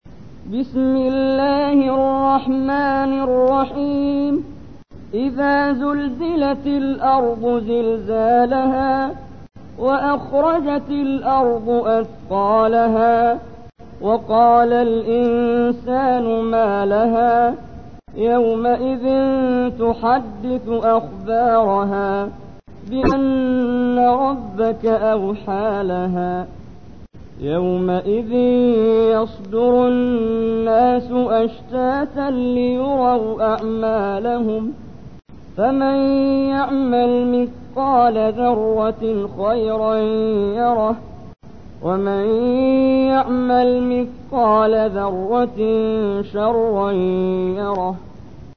تحميل : 99. سورة الزلزلة / القارئ محمد جبريل / القرآن الكريم / موقع يا حسين